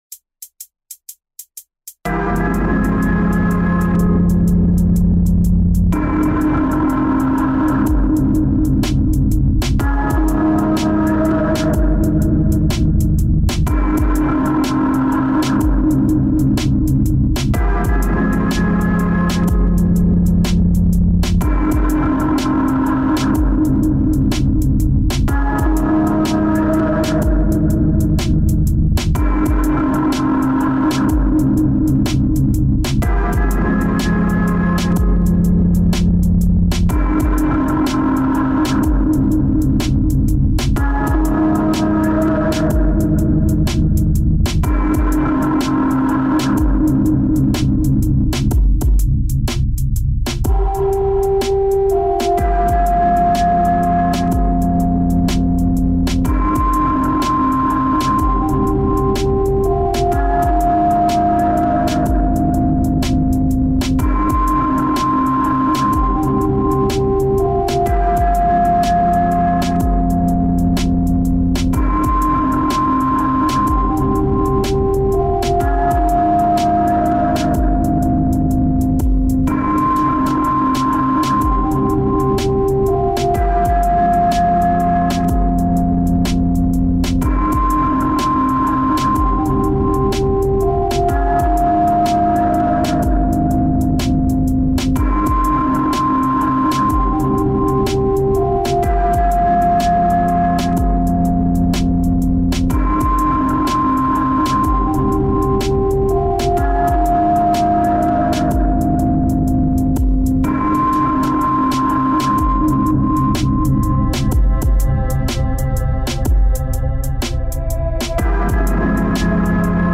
сыро и плоско, как коврик в ванной.
ещё и рвано, как будто коврик пытались засунуть в бельевую корзину.